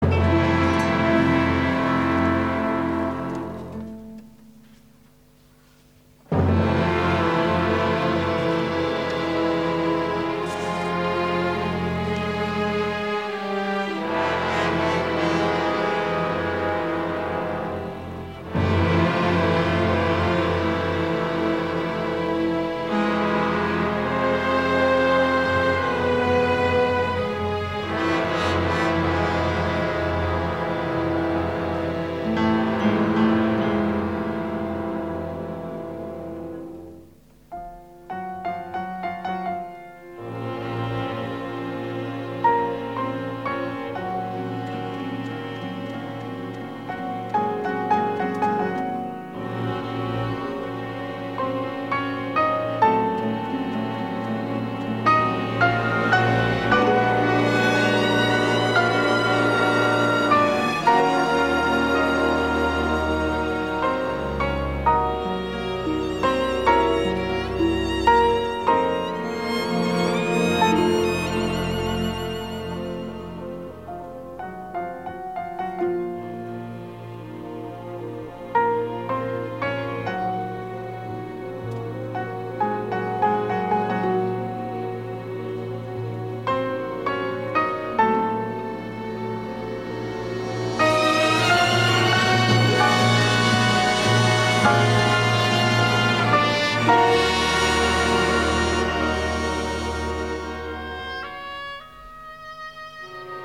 impetuosa banda sonora